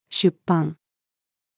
出版-female.mp3